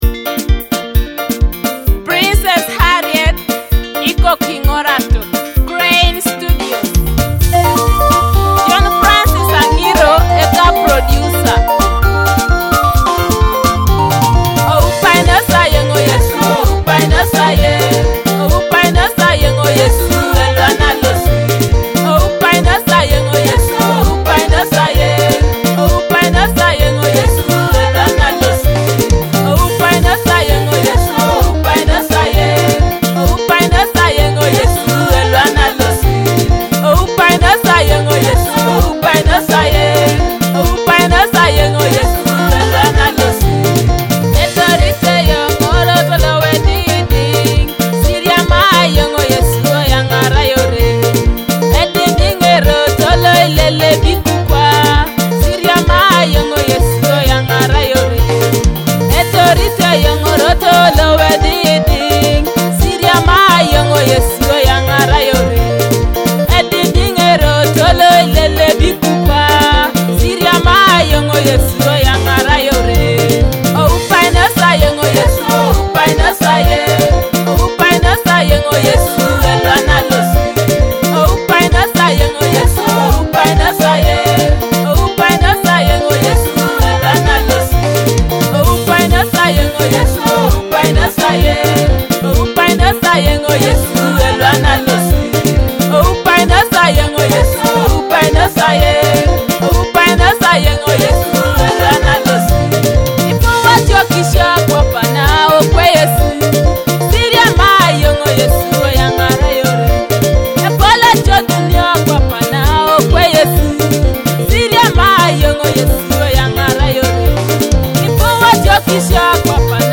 a powerful Teso gospel song
Teso gospel song